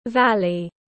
Thung lũng tiếng anh gọi là valley, phiên âm tiếng anh đọc là /ˈvæl.i/.
Valley /ˈvæl.i/